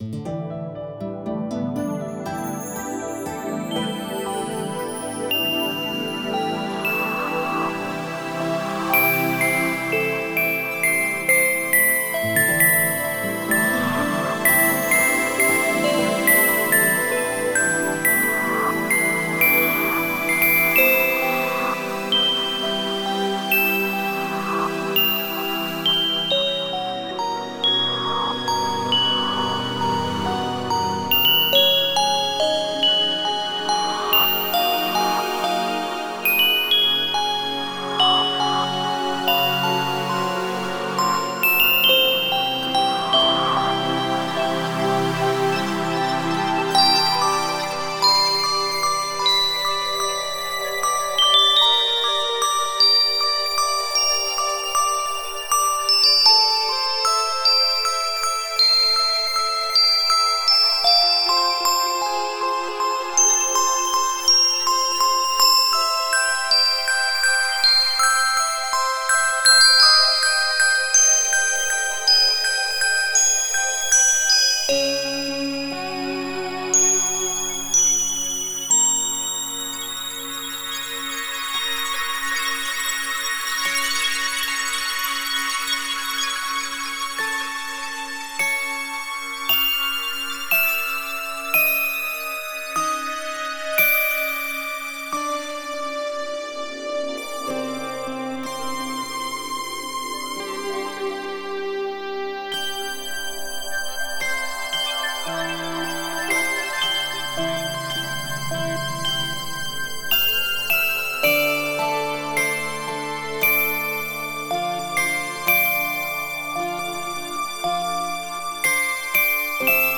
Духовная музыка Медитативная музыка Мистическая музыка